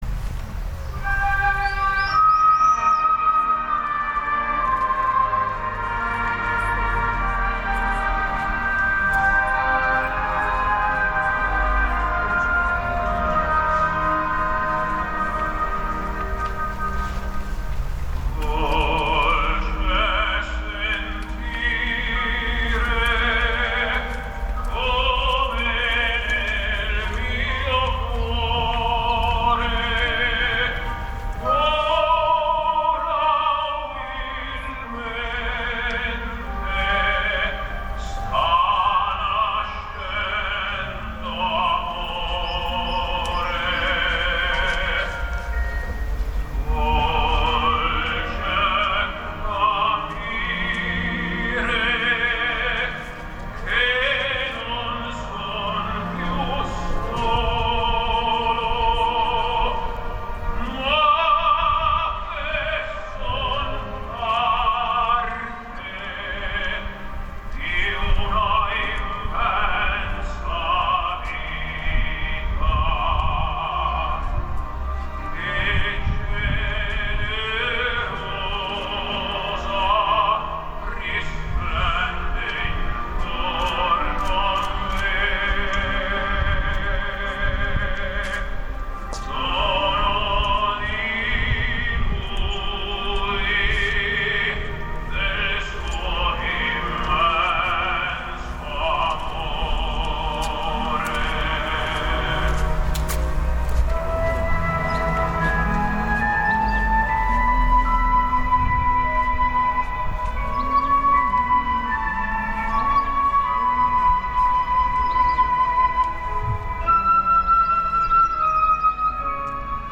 Per restrizioni da pandemia, tutto si è svolto presso il locale Museo dei Misteri, dove il vescovo Mons. Giancarlo Maria Bregantini ha celebrata la Messa, in un’atmosfera che comunque è stata degna di nota.
Dolce Sentire, evidentemente gradita anche dai numerosi uccelli presenti nel cielo sovrastante.